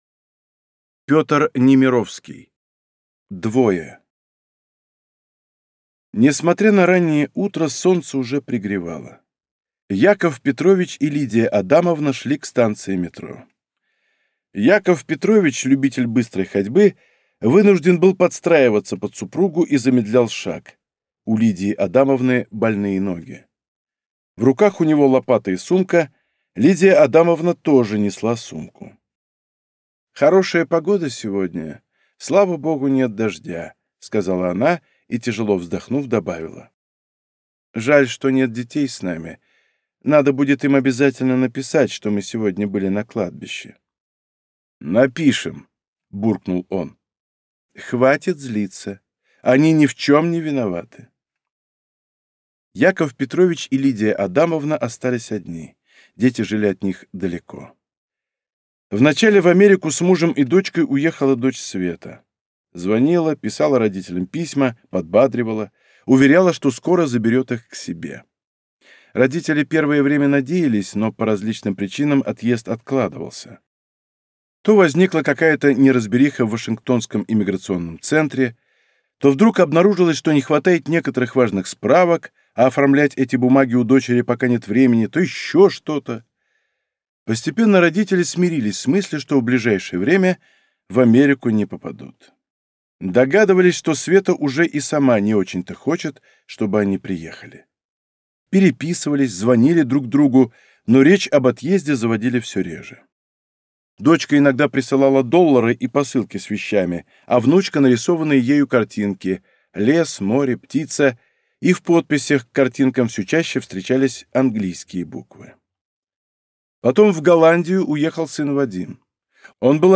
Аудиокнига Двое | Библиотека аудиокниг